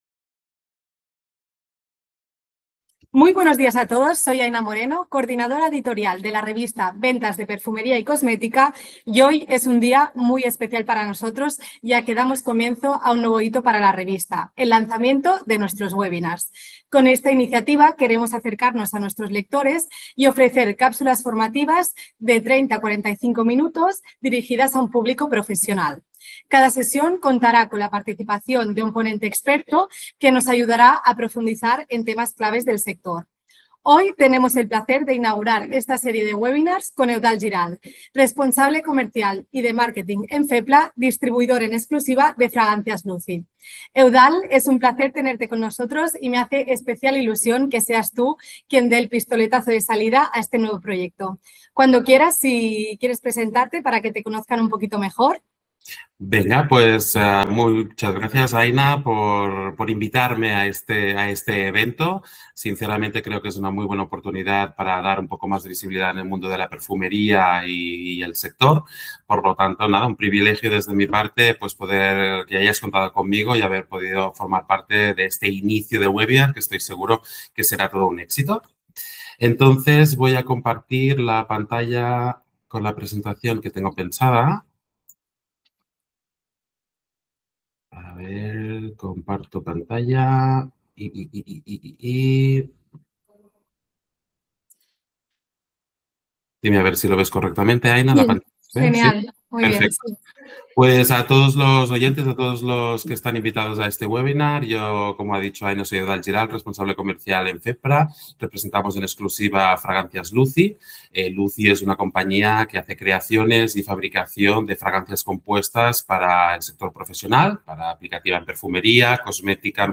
Un webinar